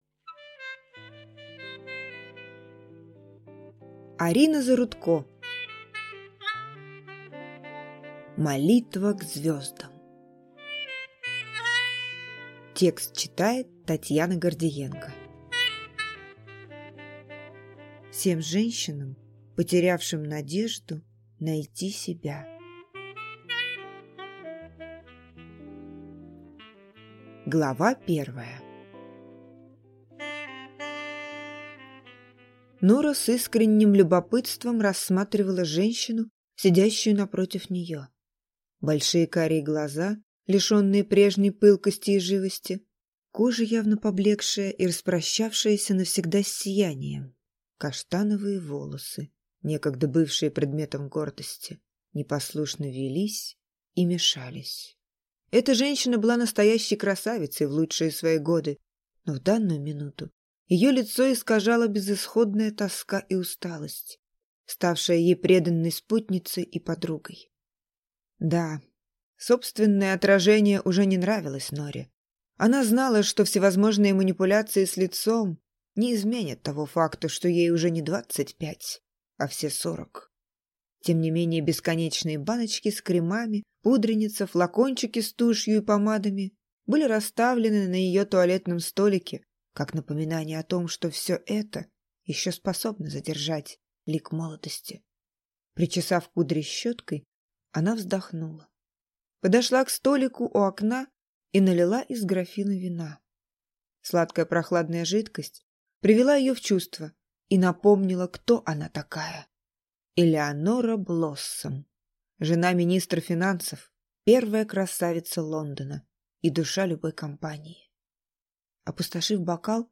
Аудиокнига Молитва к звездам | Библиотека аудиокниг
Прослушать и бесплатно скачать фрагмент аудиокниги